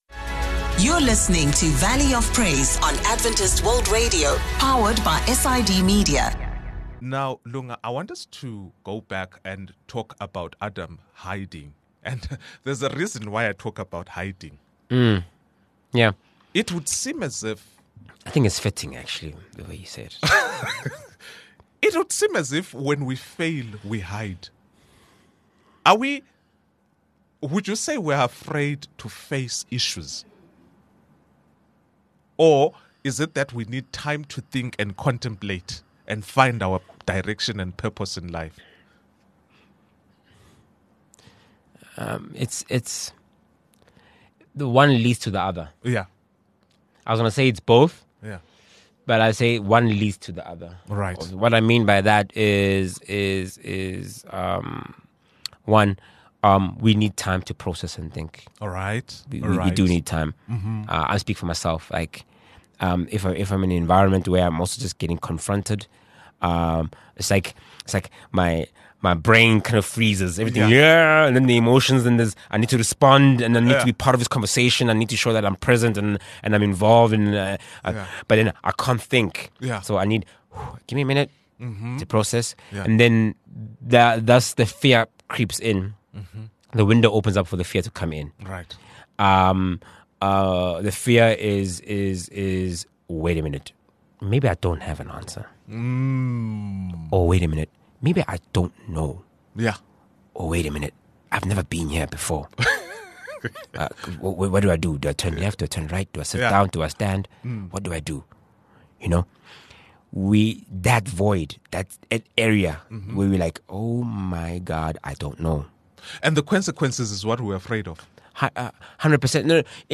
Discover how faith, vulnerability, and community can guide men to healing, purpose, and transformation. A powerful conversation on letting God lead the way!